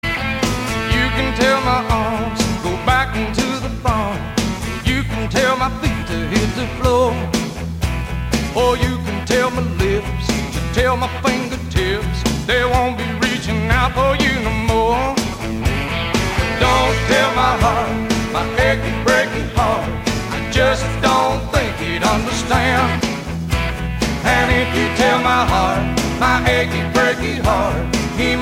Besetzung: Blasorchester
As-Dur